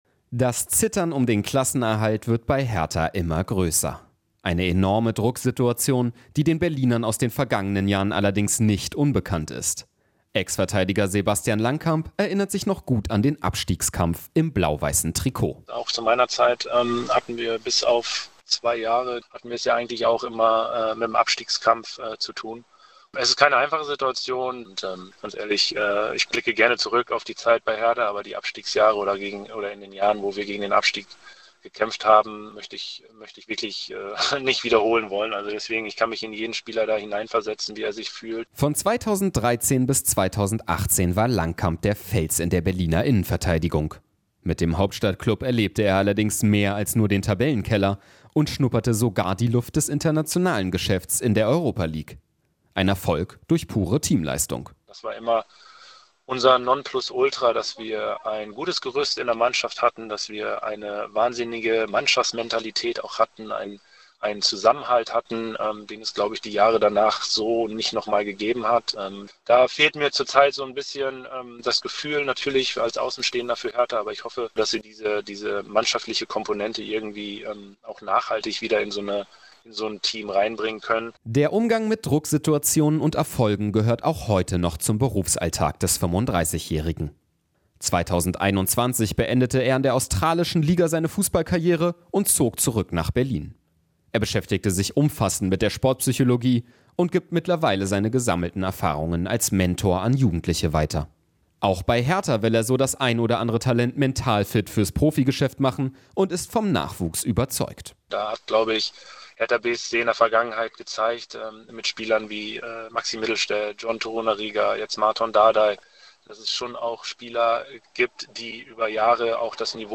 Inforadio Nachrichten, 13.05.2023, 20:30 Uhr - 13.05.2023